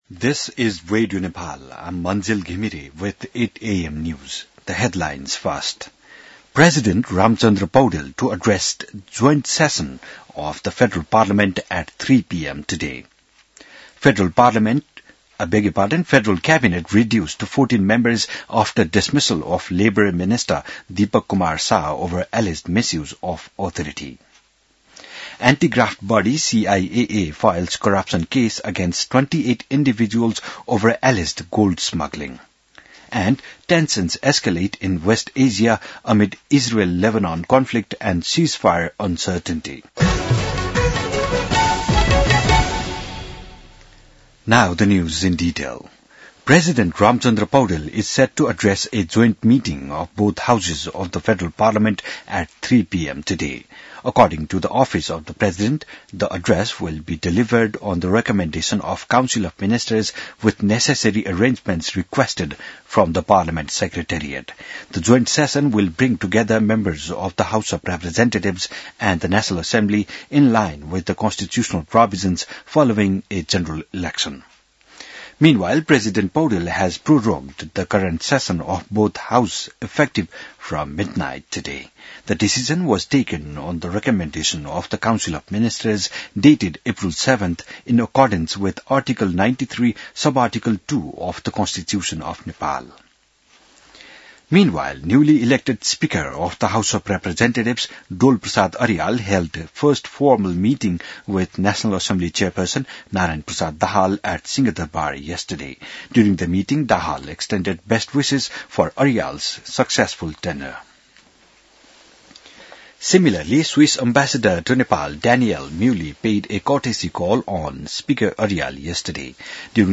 बिहान ८ बजेको अङ्ग्रेजी समाचार : २७ चैत , २०८२